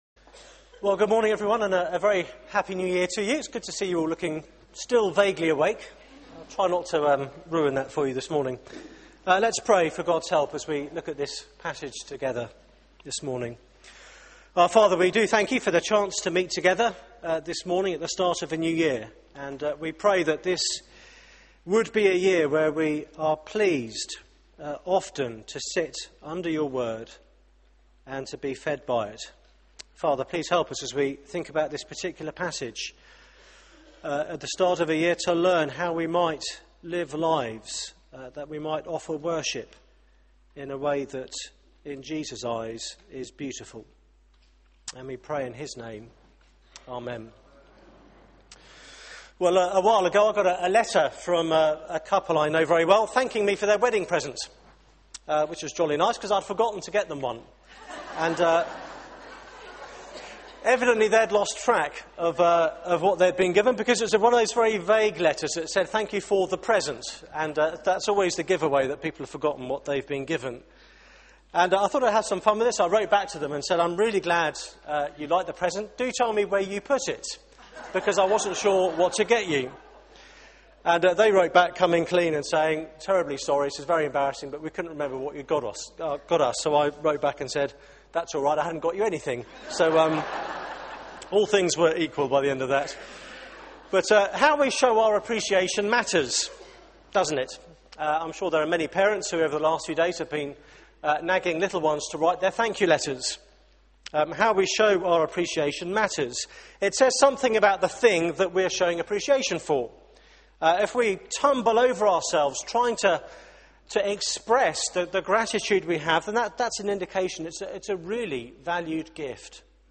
Media for 9:15am Service on Sun 01st Jan 2012 11:00 Speaker